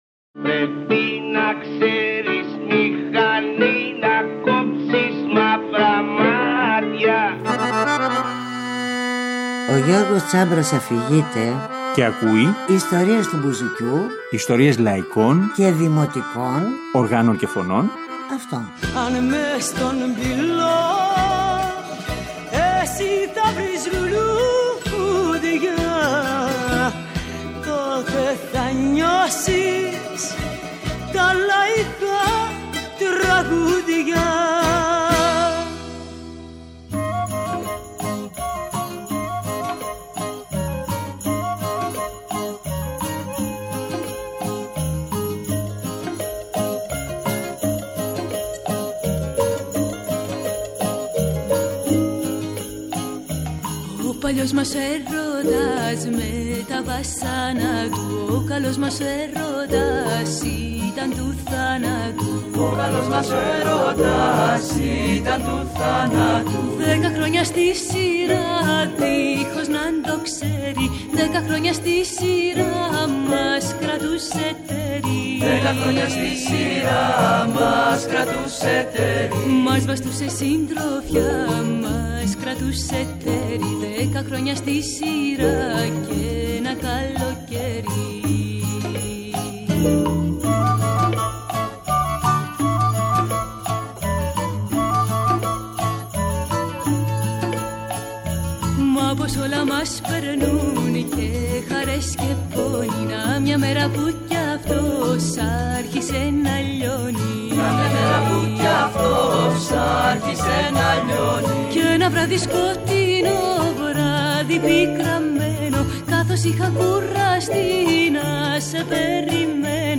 Στην εκπομπή, ακούμε μια επιλογή απ’ αυτό το υλικό, περιγράφουμε το κλίμα μέσα στο οποίο δημιουργήθηκε και διαβάζουμε αφηγήσεις από συνεντεύξεις εποχής.